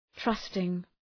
Προφορά
{‘trʌstıŋ}